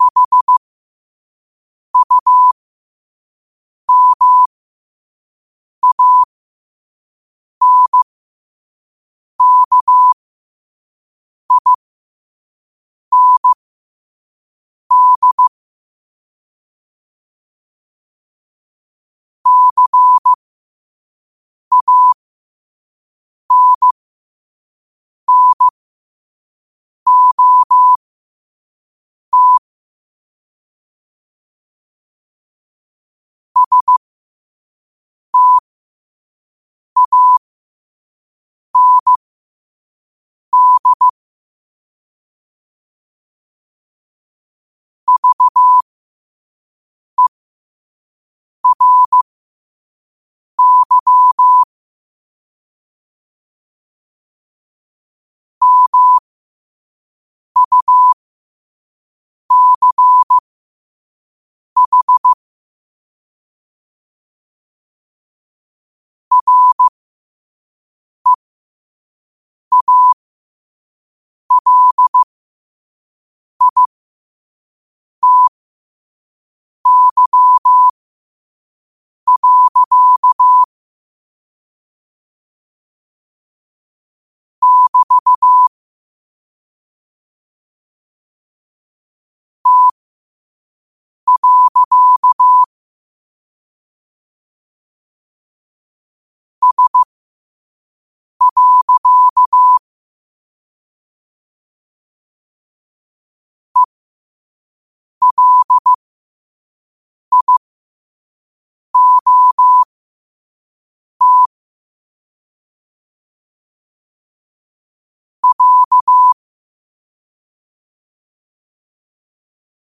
Quotes for Thu, 14 Aug 2025 in Morse Code at 5 words per minute.